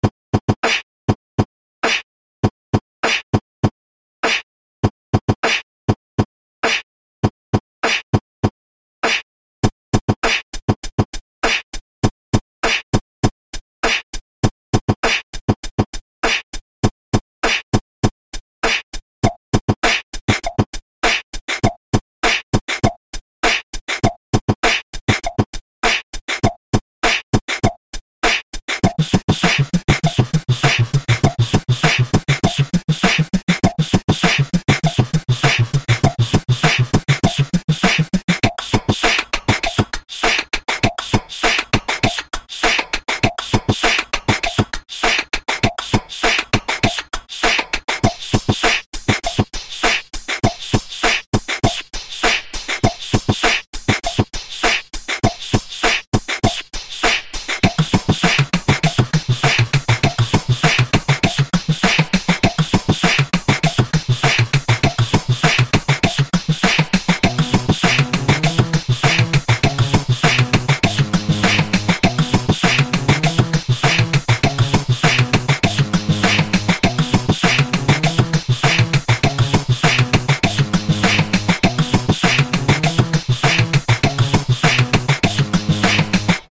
Форум российского битбокс портала » Реорганизация форума - РЕСТАВРАЦИЯ » Выкладываем видео / аудио с битбоксом » Ну, раз ФЛ, значит ФЛ) (Мои звуки, моё редактирование, ничего искуственного.)
Ну я показал, как смог совместить почти все звуки в одном потоке, могу и минус сделать, он уж точно не будет разнообразным.
У здесь меня не получился лип осалейшен, продлённые хэты и тема с пуфф снером, а вот из остального можно было бы сделать минус, если б звуки были записаны не на мой микрофон за сто рублей)